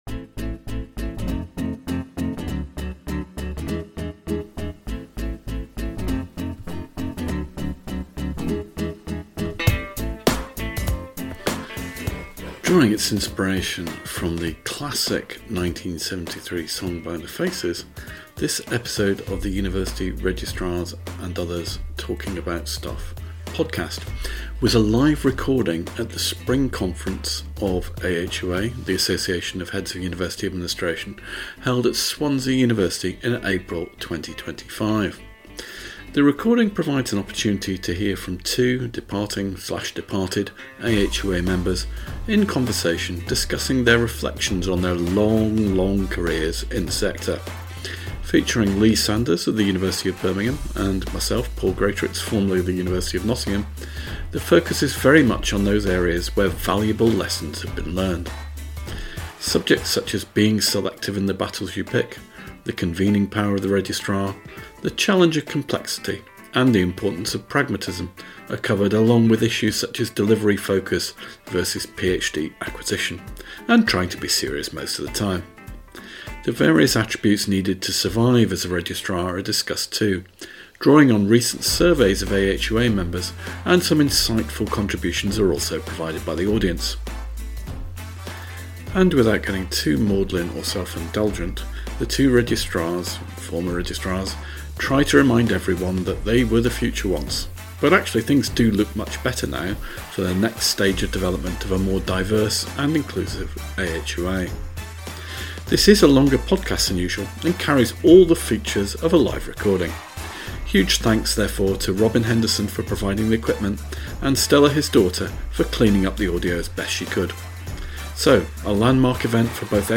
A series of podcasts in which two Registrars (or similar) talk about higher ed stuff.